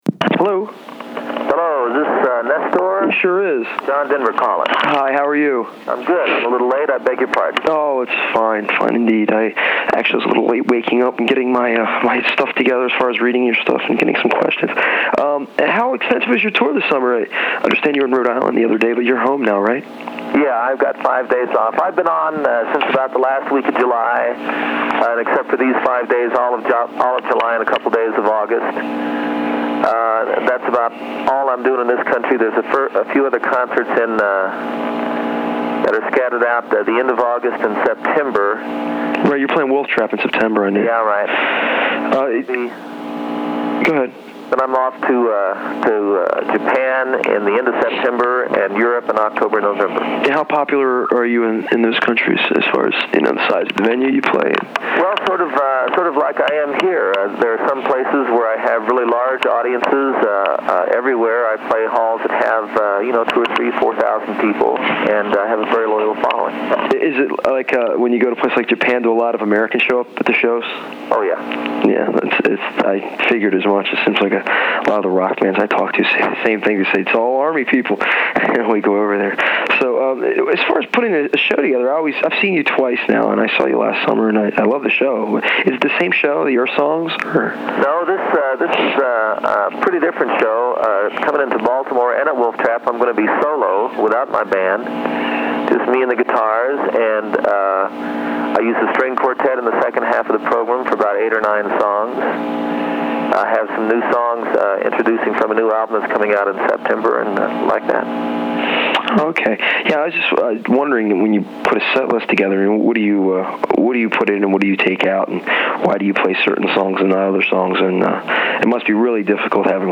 This is a depth-packed discussion with the guy who made the 7th inning stretch at Orioles games feel like life on the farm...